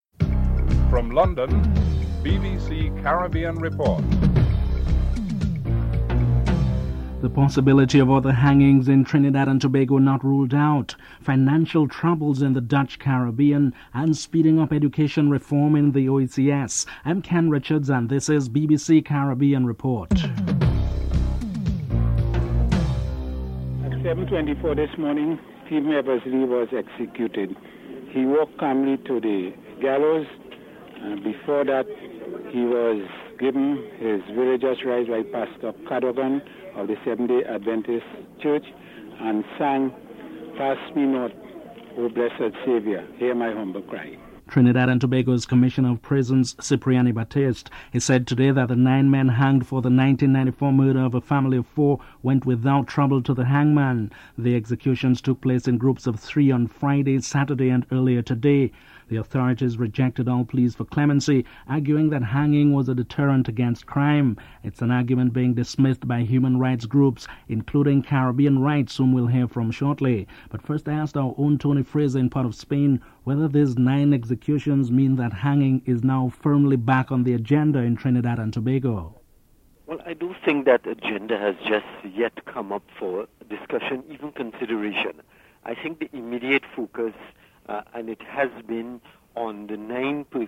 Jamaican nationals speak out on the issue (00: 24 - 06:11)